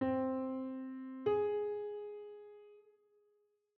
Minor 6th
C-Minor-Sixth-Interval-S1.wav